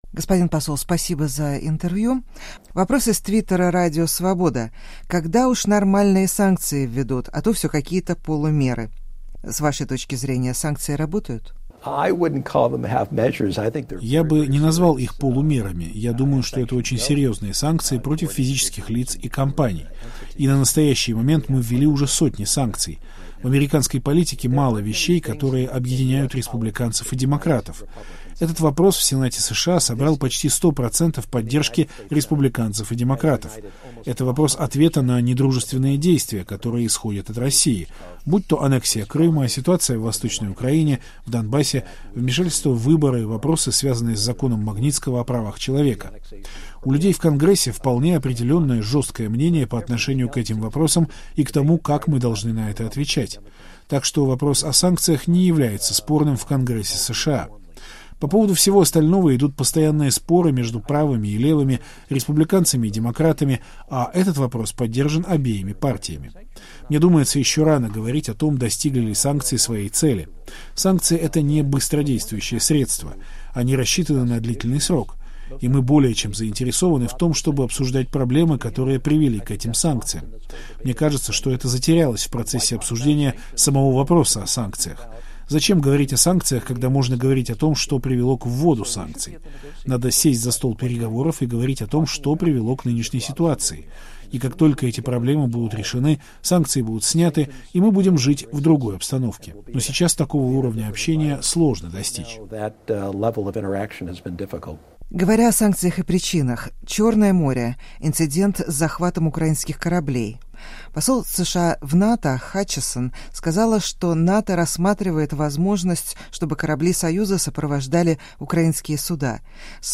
Однако видимых изменений в политике президента Путина не произошло. Об этом – разговор с послом США в России Джоном Хантсманом.